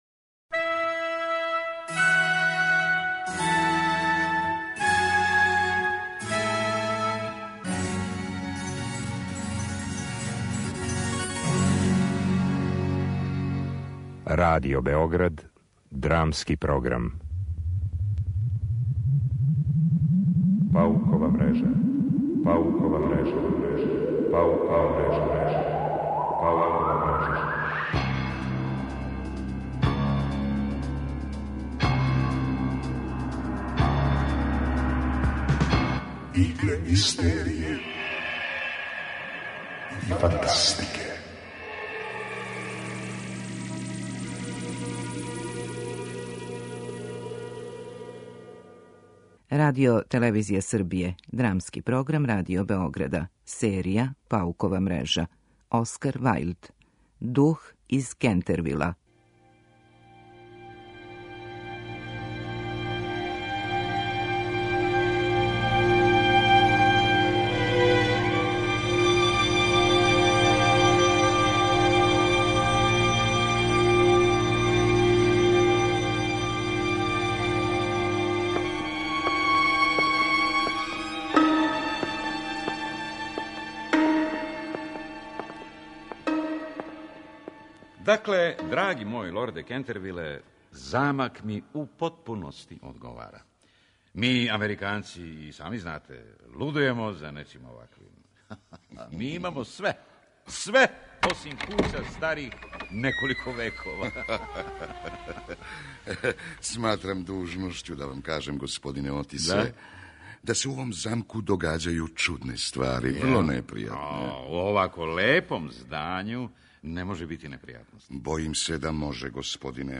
Драмски програм: Паукова мрежа
У радио-драматизацији истоимене Вајлдове кратке приче, породица богатог Американца досељава се у напуштену кућу на имању Кентервил коју бије глас да је опседају духови. Ћерка се зближава са духом помажући му да се достојно упокоји.